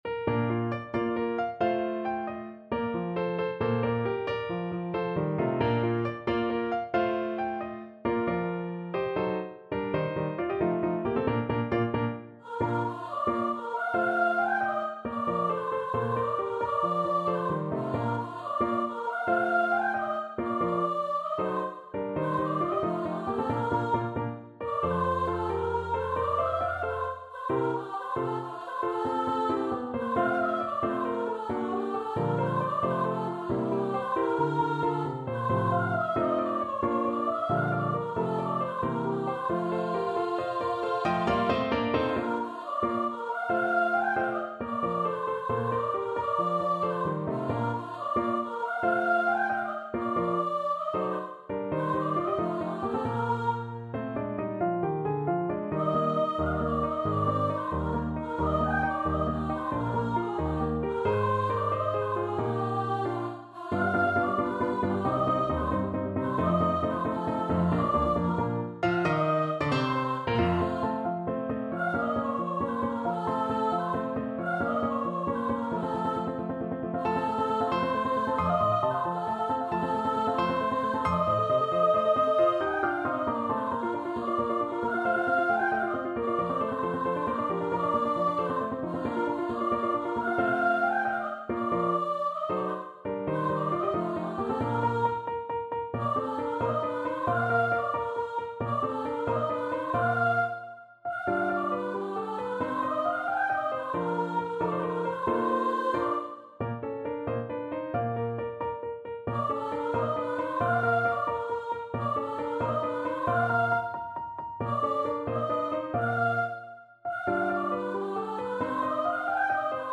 Mezzo Soprano Voice
Bb major (Sounding Pitch) (View more Bb major Music for Mezzo Soprano Voice )
6/8 (View more 6/8 Music)
. = 90 Allegretto vivace
Classical (View more Classical Mezzo Soprano Voice Music)